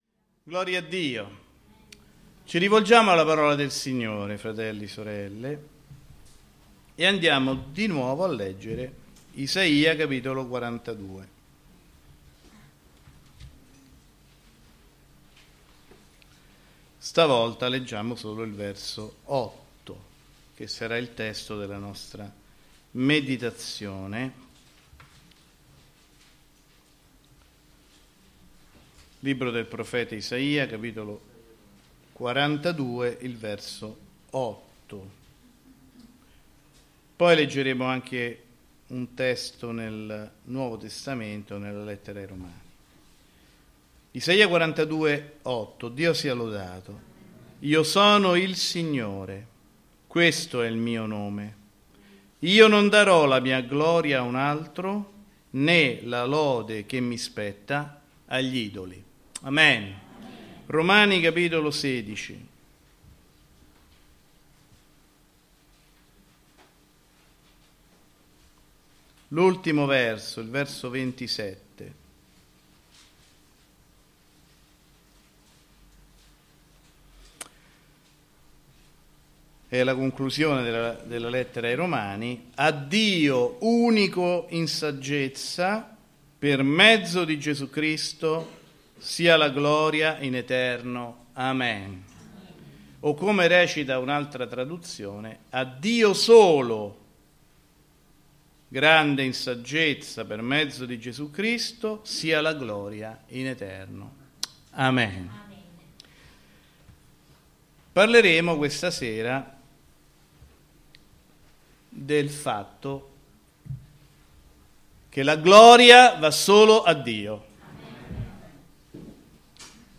Predicatore